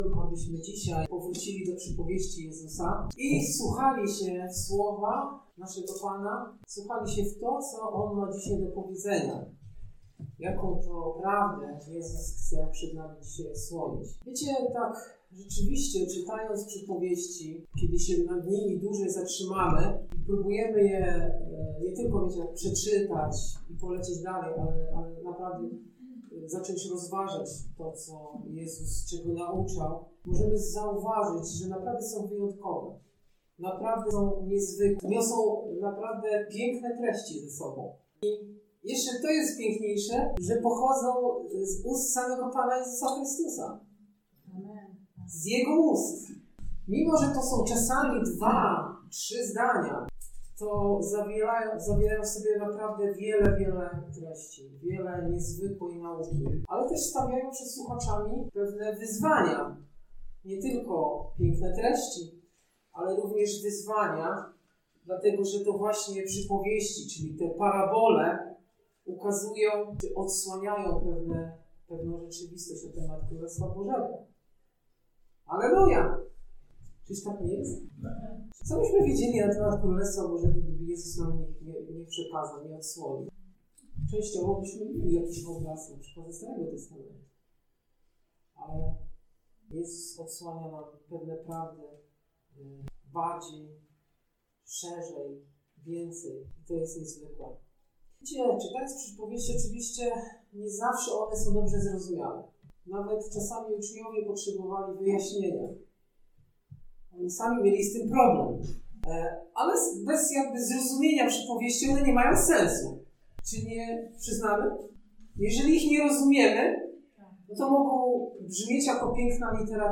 Posłuchaj kazań wygłoszonych w Zborze Słowo Życia w Olsztynie. Kazanie